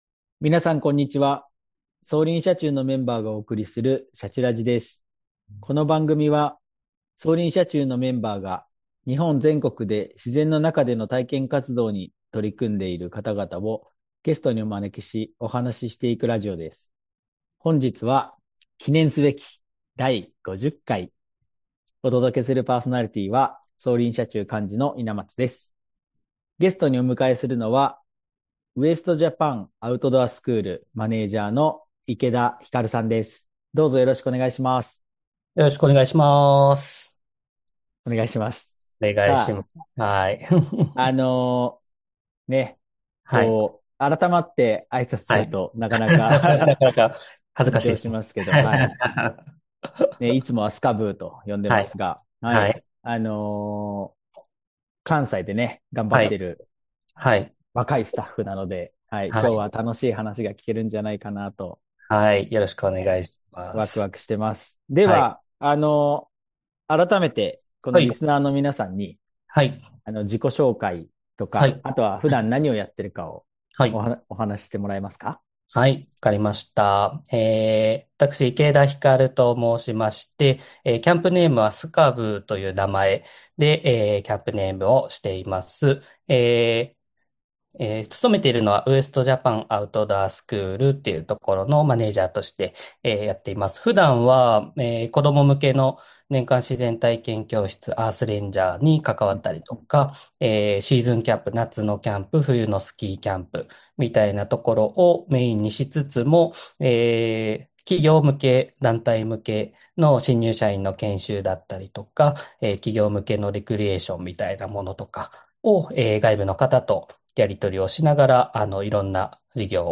【今回のゲストスピーカー】